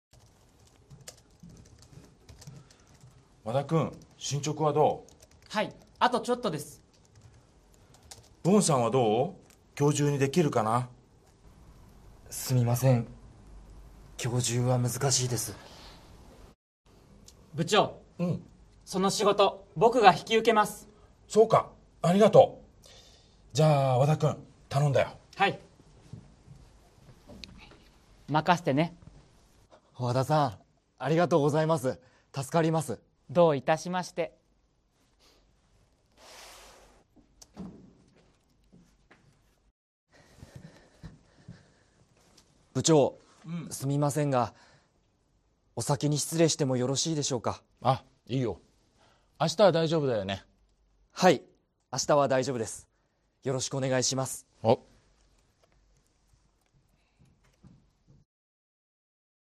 Role-play Setup
Scene: A company office where employees are finishing their work for the day.